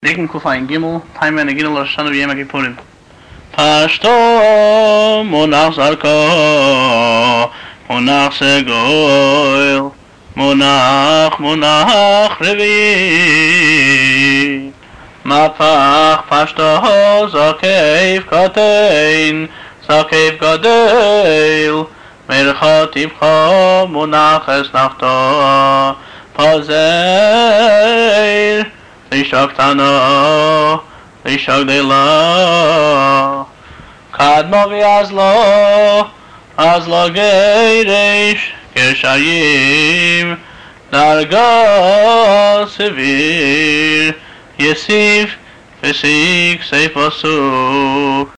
טעמי המקרא